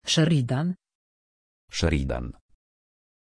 Pronunciation of Sheridan
pronunciation-sheridan-pl.mp3